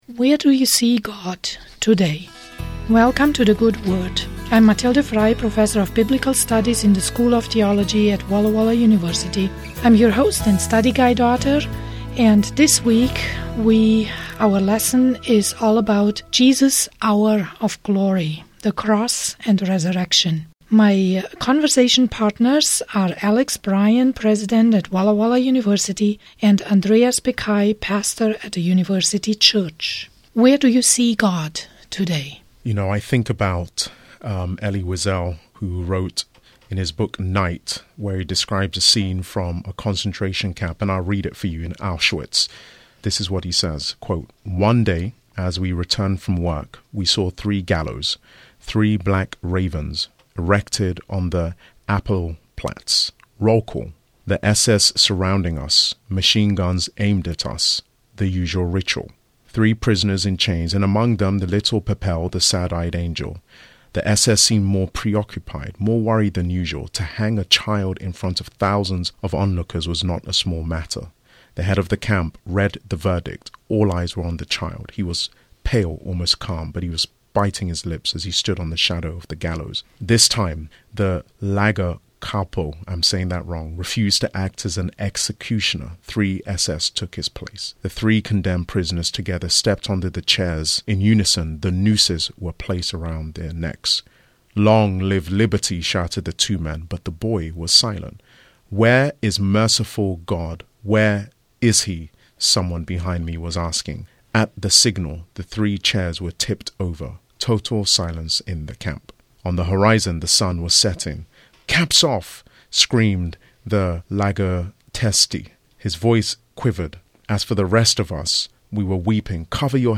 Lesson: 12